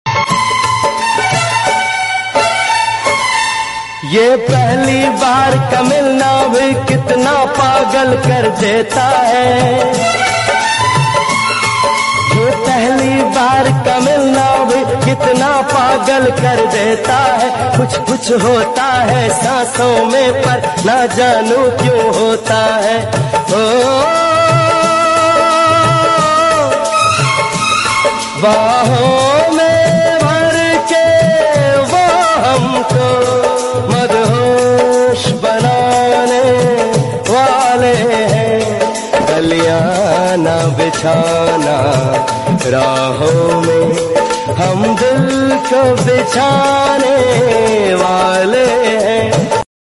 Snake matting in DHA Lahore sound effects free download